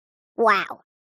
Звуки мультяшные